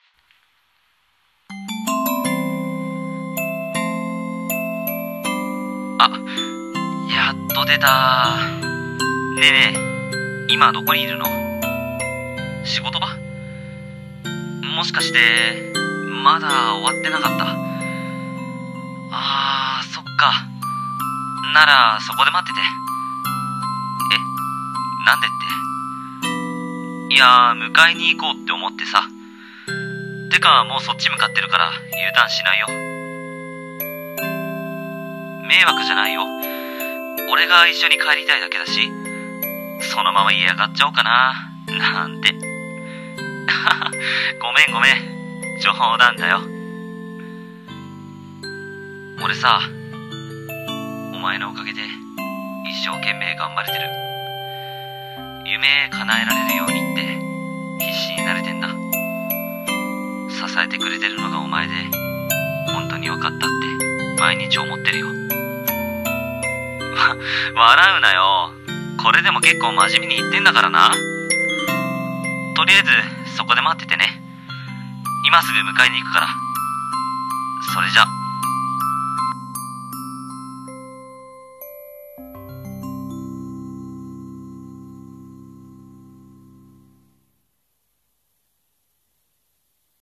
《声劇》電話～背中を押してくれる君に～